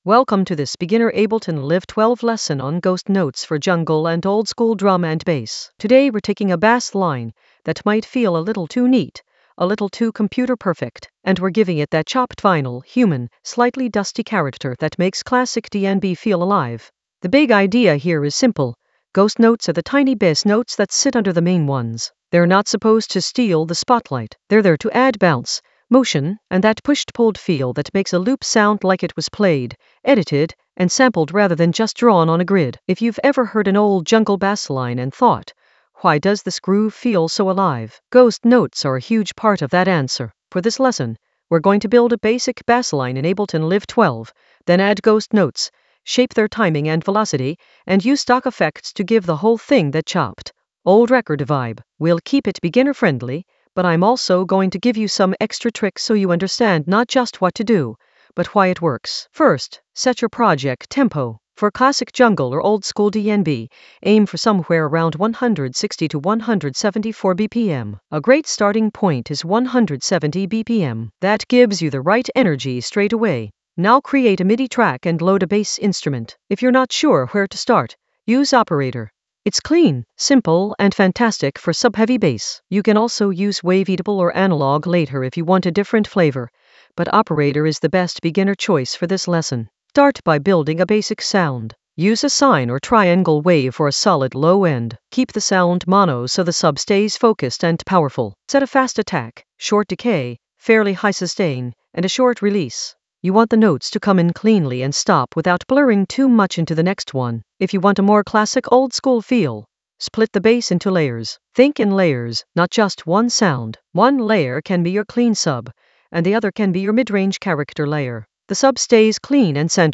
An AI-generated beginner Ableton lesson focused on Ghost note in Ableton Live 12: humanize it with chopped-vinyl character for jungle oldskool DnB vibes in the Basslines area of drum and bass production.
Narrated lesson audio
The voice track includes the tutorial plus extra teacher commentary.